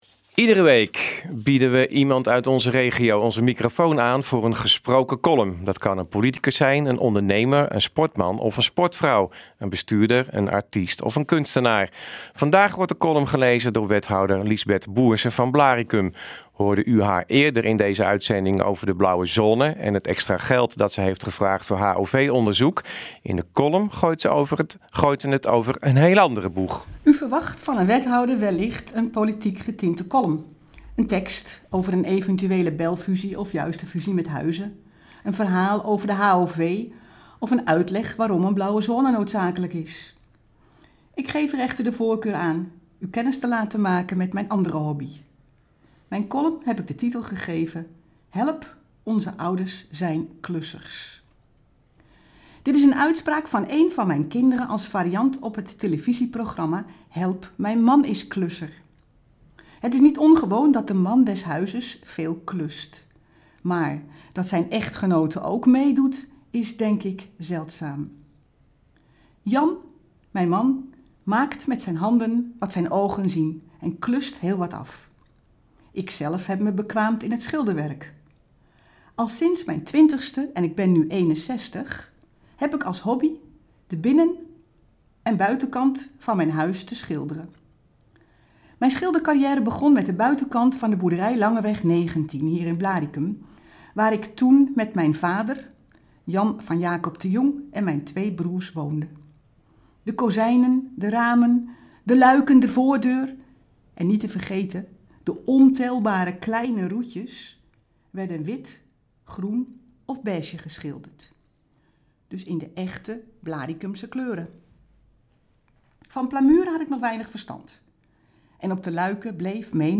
Onze wekelijkse column wordt deze week gesproken door wethouder Liesbeth Boersen van Blaricum.